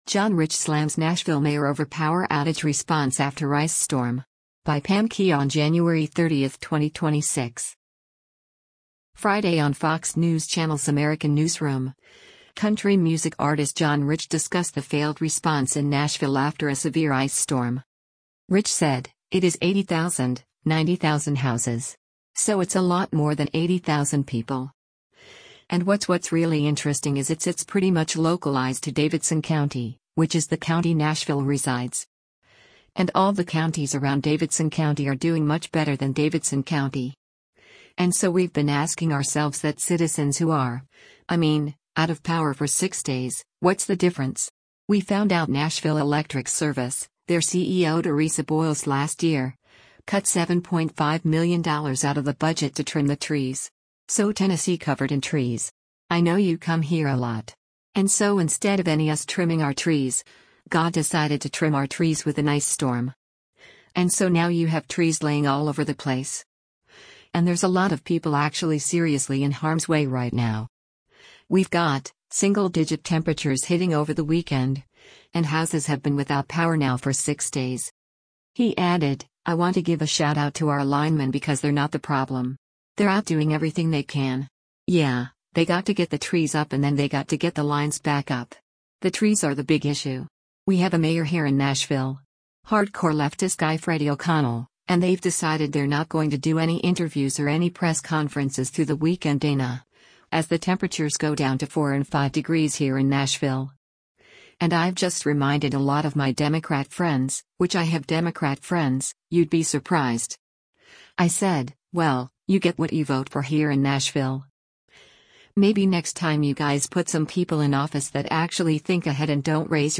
Friday on Fox News Channel’s “American Newsroom,” country music artist John Rich discussed the failed response in Nashville after a severe ice storm.